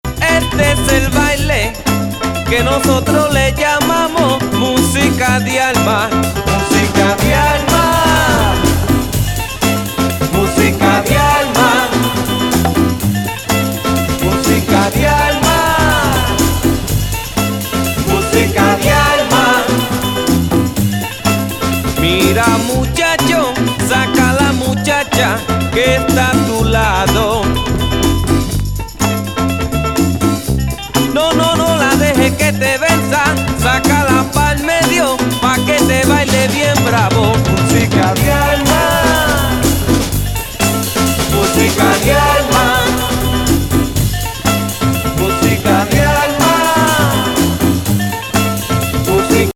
LATIN×BOOGALOO×SOUL×FUNKが洒脱にブレンド。